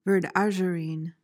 PRONUNCIATION:
(vuhrd-AZH-uh-reen)